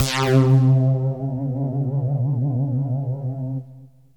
SYNTH LEADS-1 0009.wav